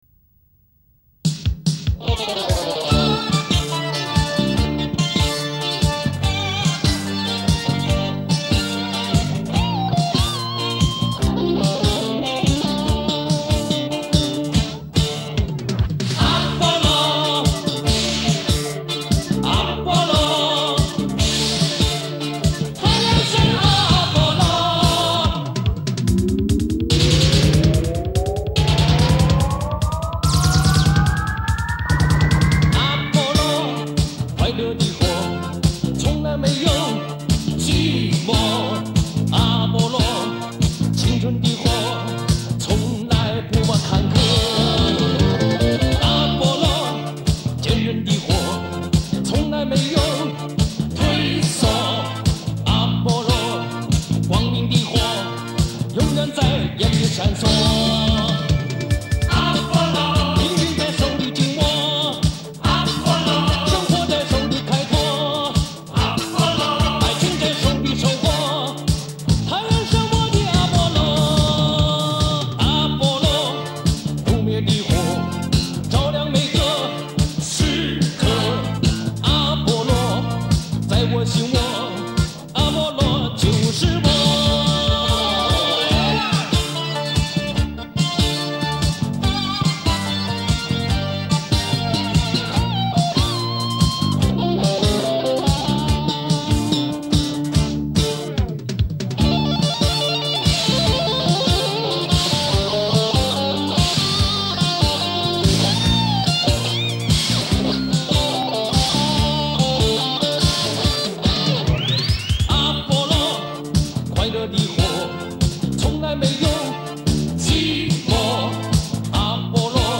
歌曲是大家熟悉的曲，重新填词的翻唱歌曲，配器在当时还是霸道的
遗憾只有160K ,效果将就。